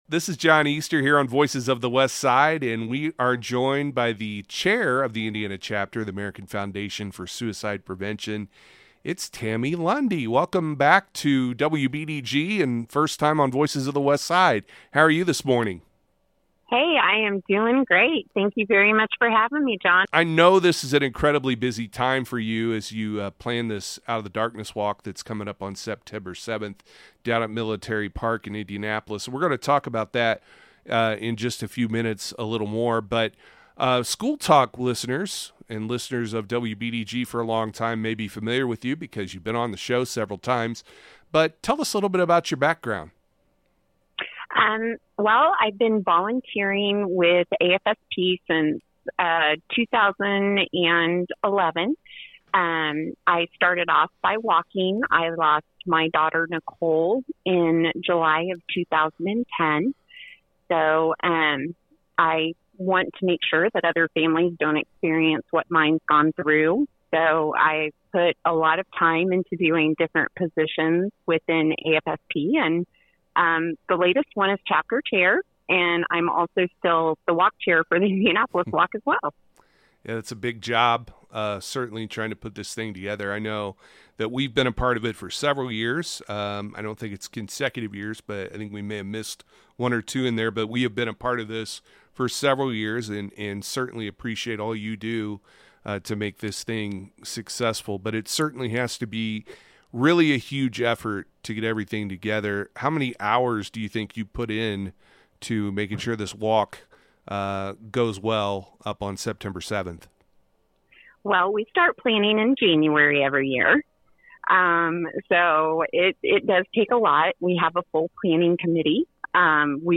Voices of the Westside Interview